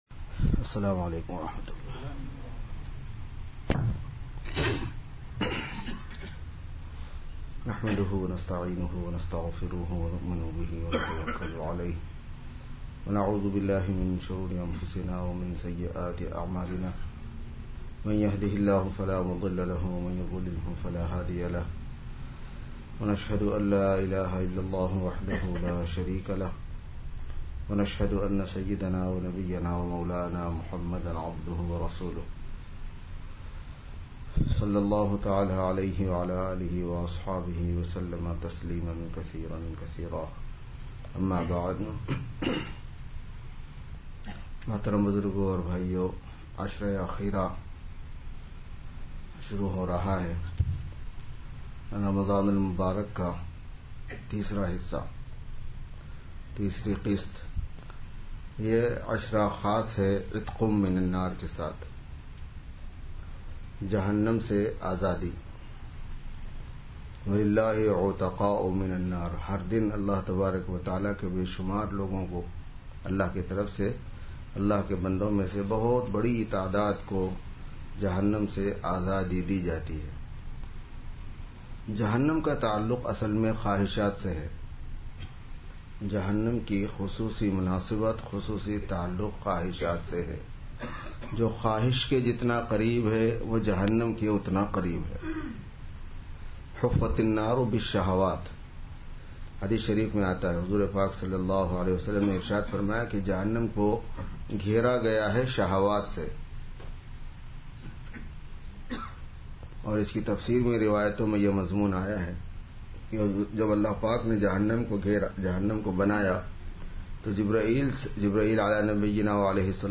Friday Sermon